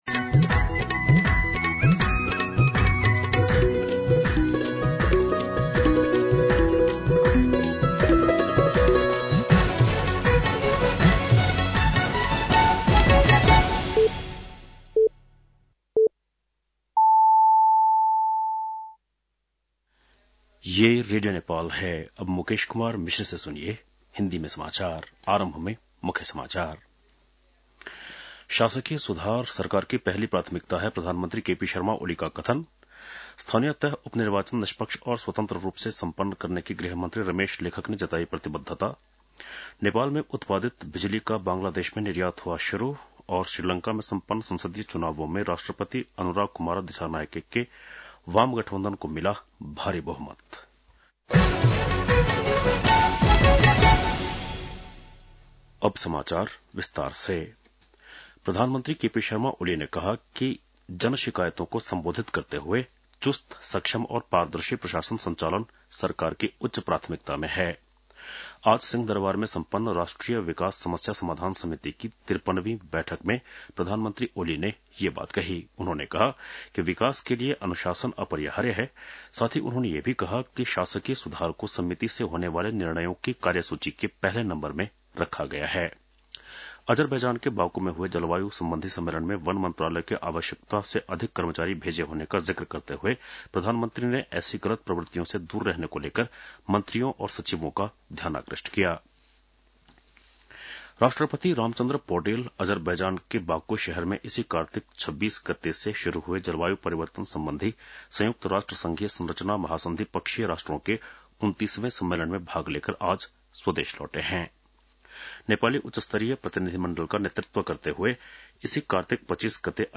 बेलुकी १० बजेको हिन्दी समाचार : १ मंसिर , २०८१
10-PM-Hindi-News-7-30.mp3